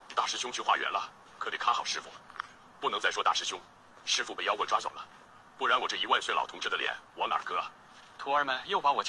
Lugn manlig röstinspelning för meditation & berättande | Noiz AI
Professionell AI för lugn manlig röstinspelning
Generera djup, lugnande och stadig röst för meditation, berättande och professionella presentationer med vår avancerade AI-röstgenerator.
Djup resonans
Stadigt tempo
Vår AI fångar de subtila nyanserna i en djup, lugn mansröst, vilket ger en känsla av auktoritet och stillhet.